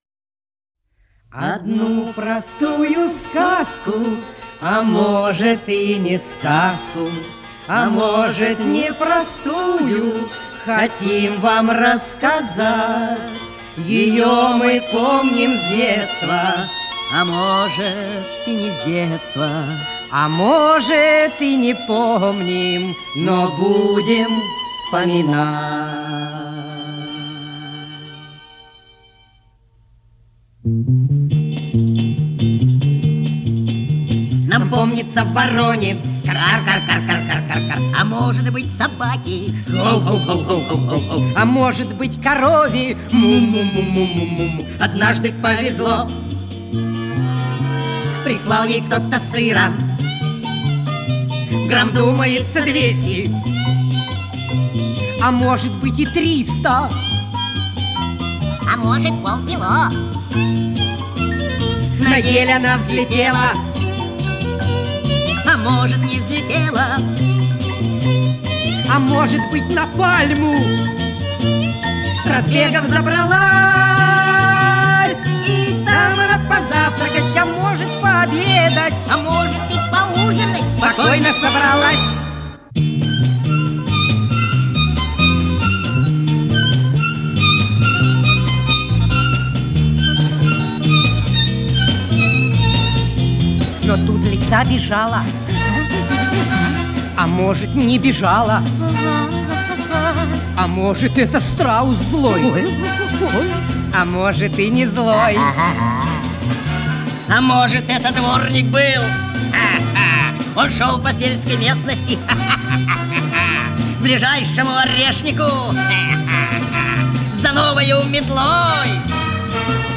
музыка и исполнение